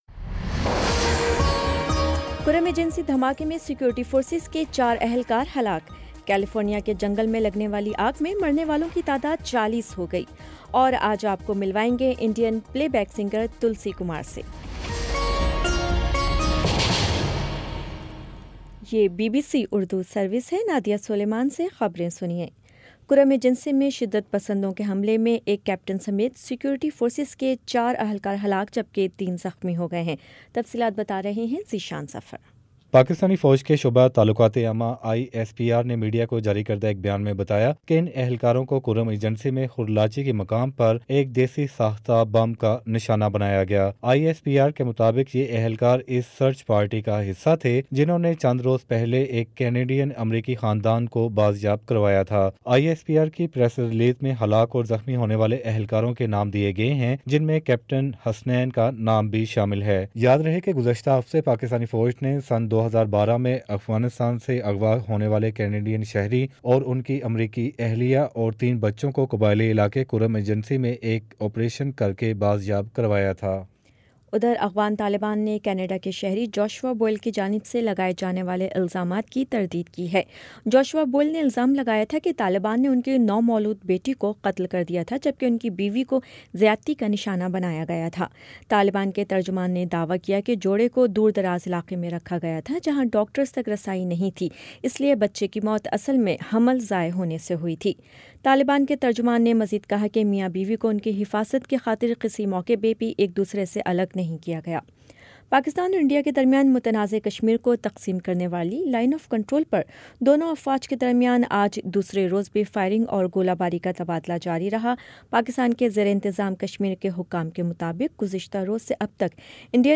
اکتوبر 15 : شام چھ بجے کا نیوز بُلیٹن